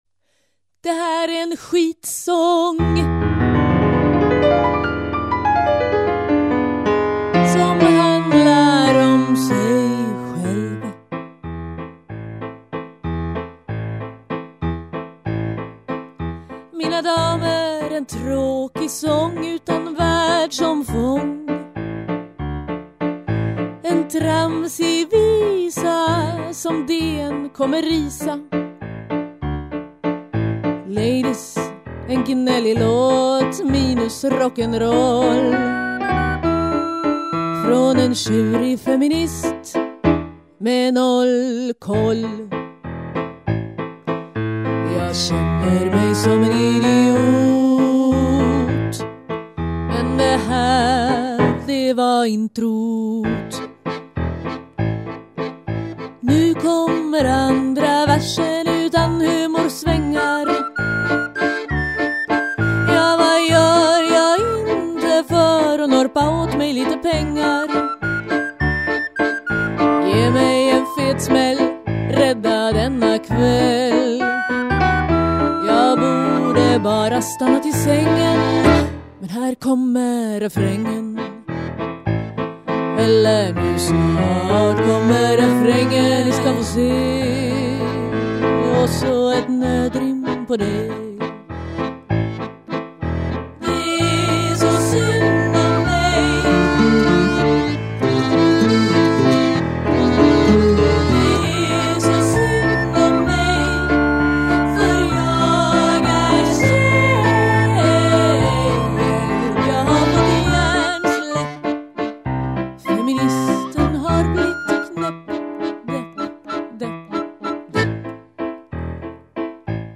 piano, dragspel, sång
Saxofon
Fiol
Trumprogrammering & synth
Cello